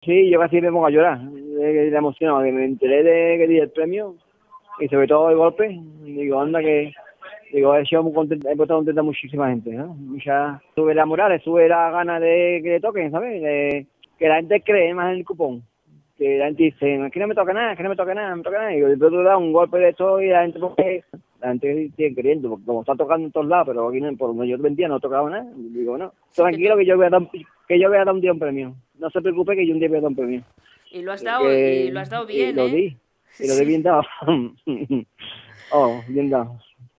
con cierto temblor en la voz formato MP3 audio(0,71 MB).
Él habla rápido, y a veces es difícil seguirle en sus expresiones al más puro estilo de la tierra.